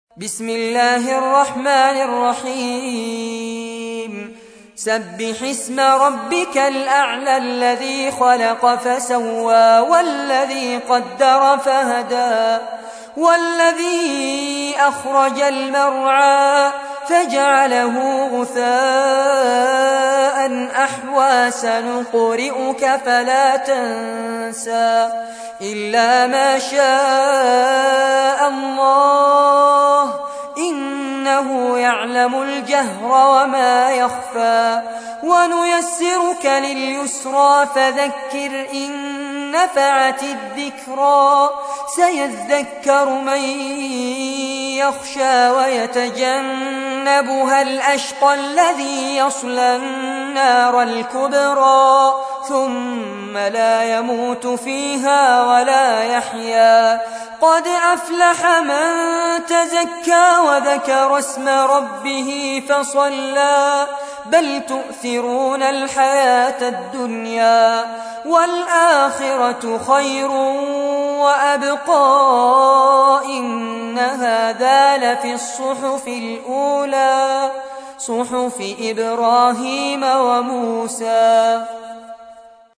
تحميل : 87. سورة الأعلى / القارئ فارس عباد / القرآن الكريم / موقع يا حسين